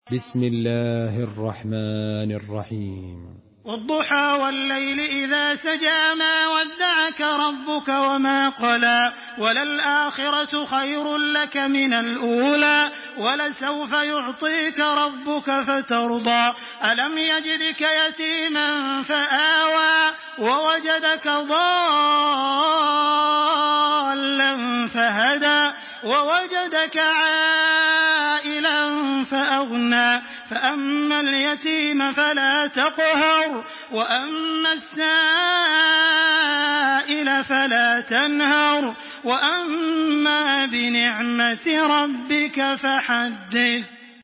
Surah الضحى MP3 by تراويح الحرم المكي 1426 in حفص عن عاصم narration.
مرتل